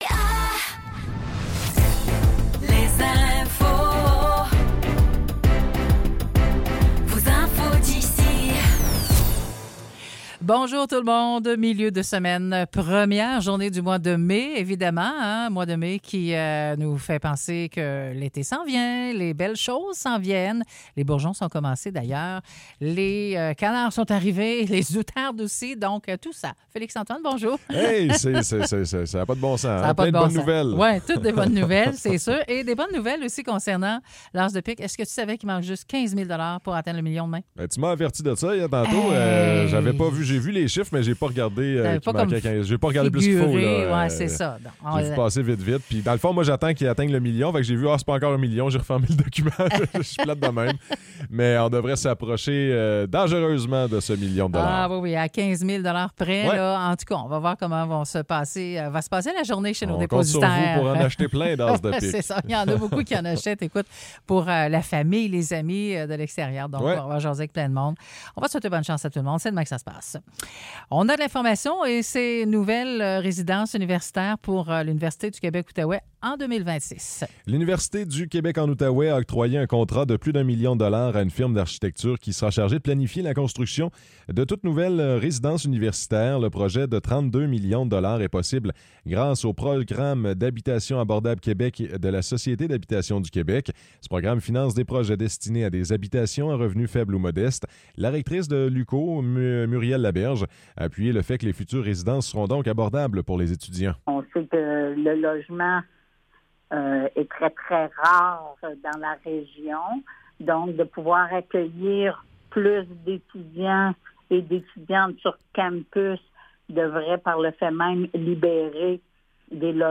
Nouvelles locales - 1er mai 2024 - 9 h